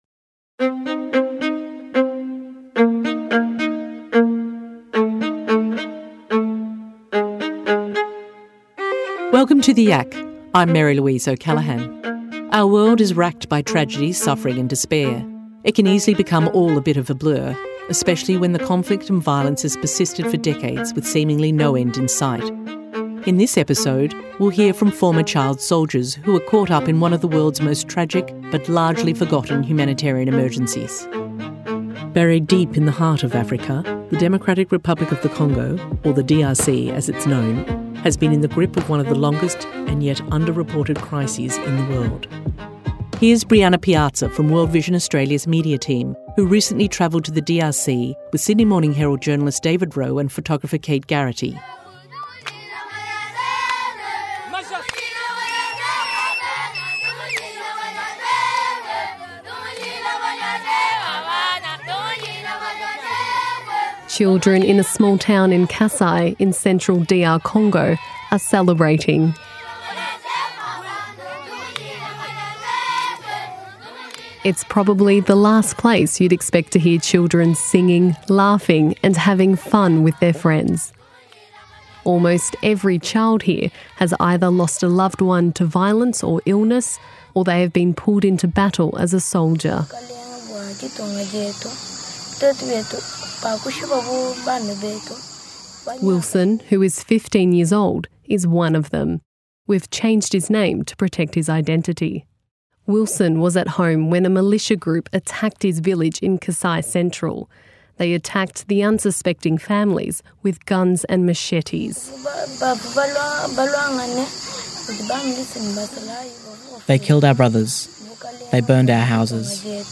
some former child soldiers themselves